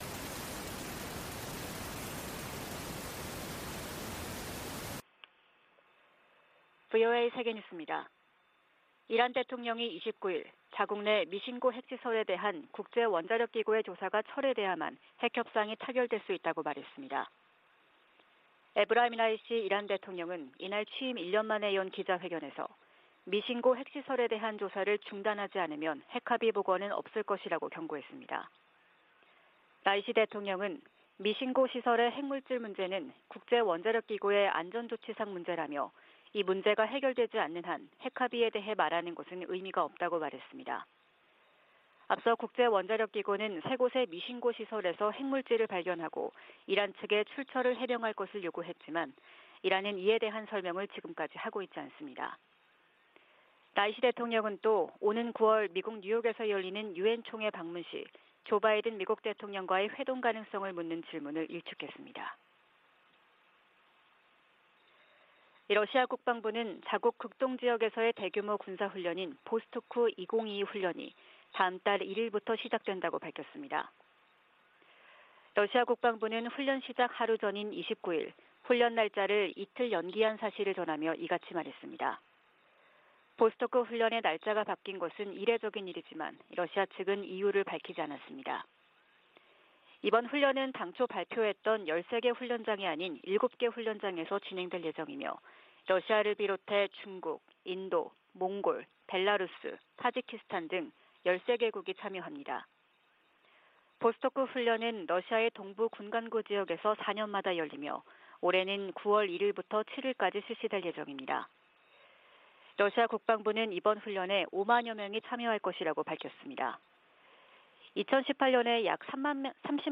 VOA 한국어 '출발 뉴스 쇼', 2022년 8월 30일 방송입니다. 북한이 핵실험 준비를 마치고 한국을 향해 보복성 대응을 언급하고 있다고 한국 국방부 장관이 말했습니다. 제10차 핵확산금지조약 (NPT) 평가회의가 러시아의 반대로 최종 선언문을 채택하지 못한 채 끝났습니다. 호주가 주관하는 피치블랙에 처음 참가하는 일본은 역내 연합훈련이 다각적이고 다층적인 안보 협력 추진으로 이어진다고 밝혔습니다.